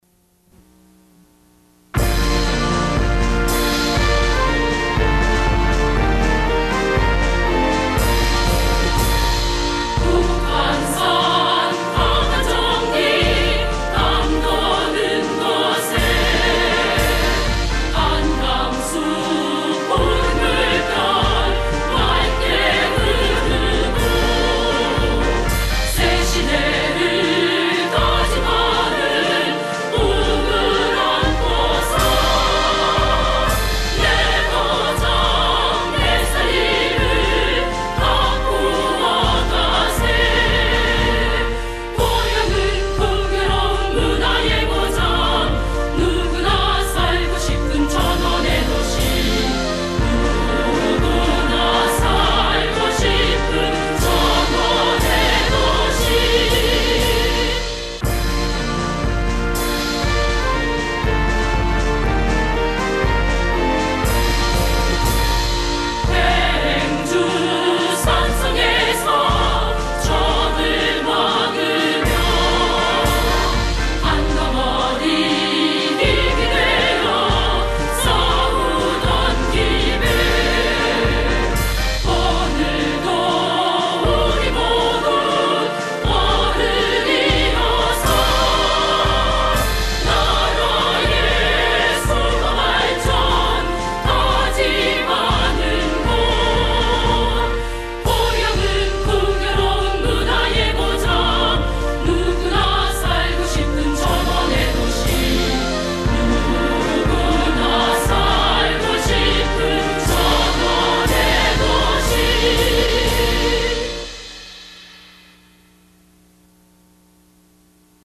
goyangcitysong_chorus.mp3